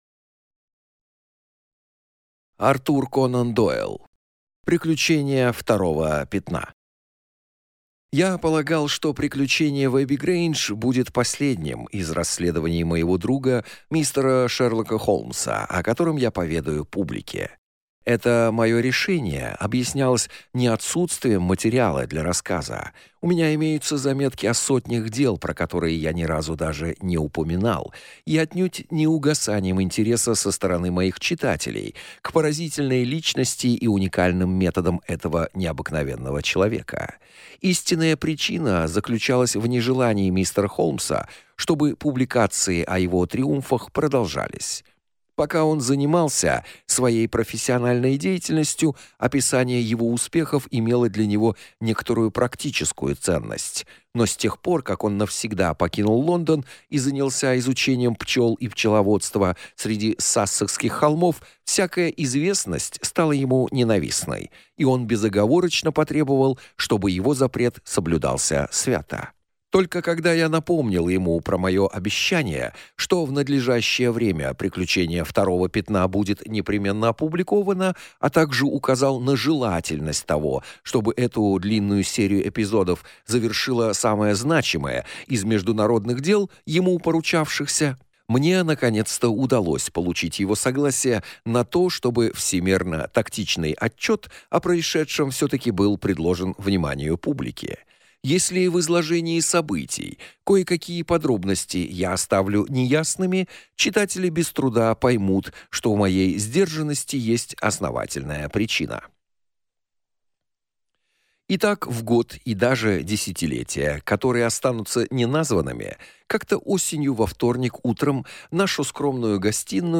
Аудиокнига Приключение второго пятна | Библиотека аудиокниг